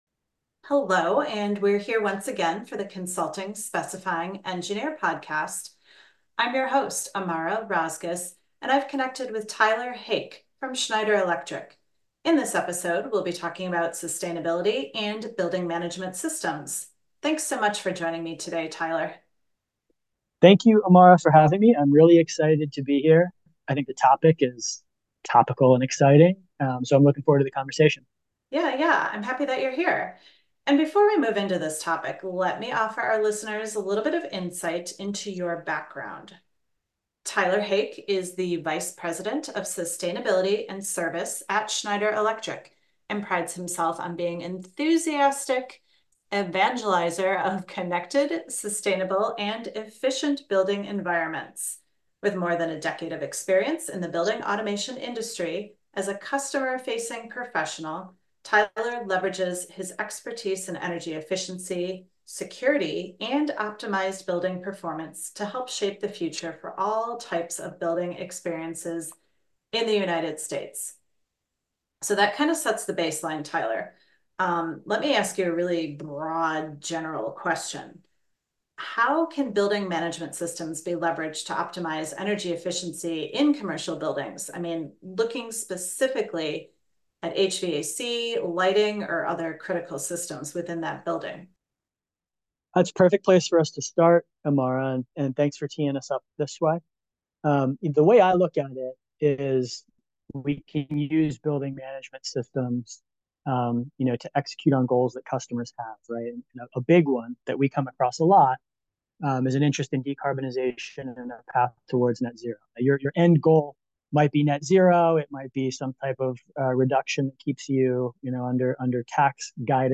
In this conversation, hear more about building management systems, integration and future trends for buildings.